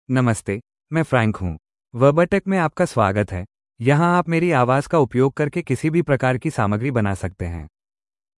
MaleHindi (India)
FrankMale Hindi AI voice
Frank is a male AI voice for Hindi (India).
Voice sample
Listen to Frank's male Hindi voice.